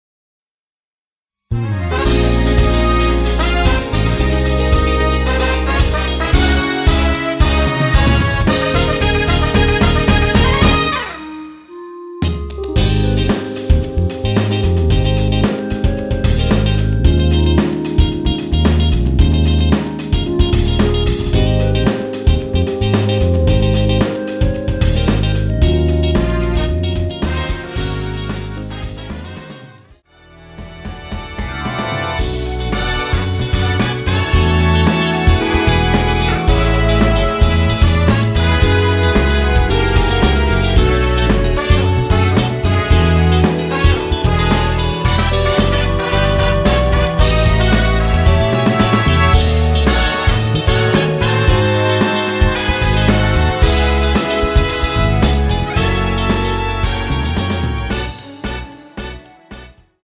원키 멜로디 포함된 MR입니다.
Ab
앞부분30초, 뒷부분30초씩 편집해서 올려 드리고 있습니다.
중간에 음이 끈어지고 다시 나오는 이유는